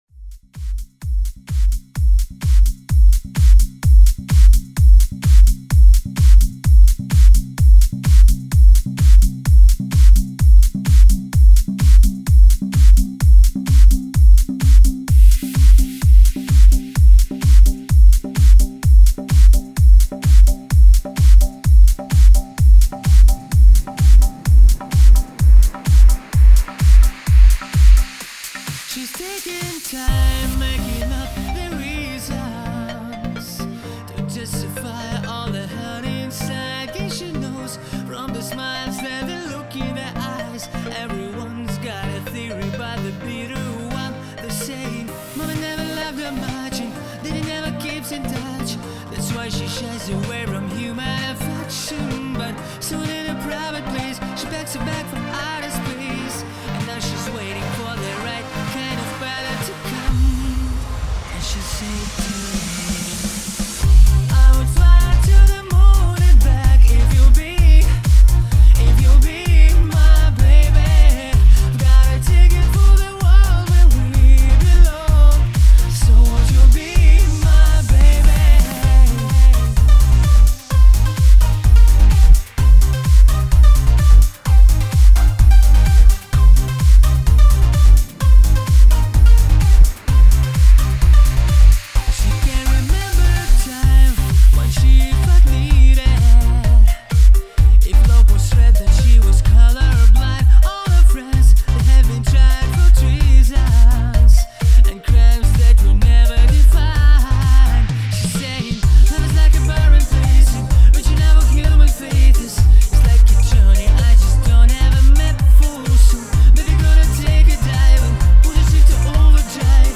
myagkij_bas_krasivaya_muzyka___2017_poneslas_xMuzic_me.mp3